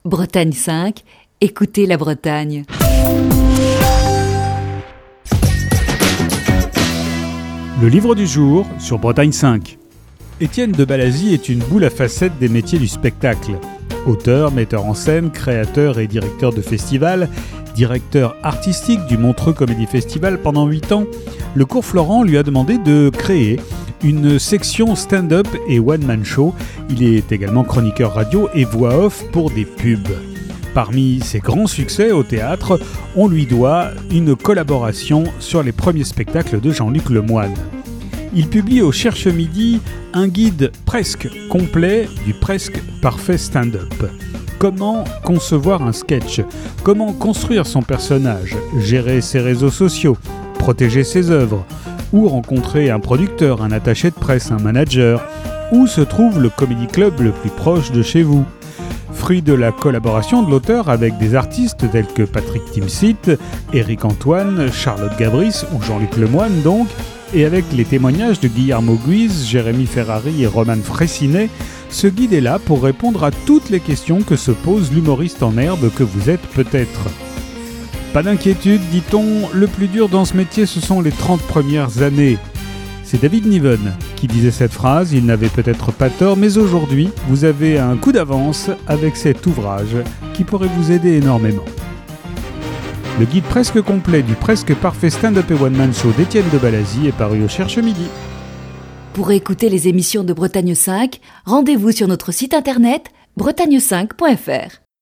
Chronique du 8 avril 2020.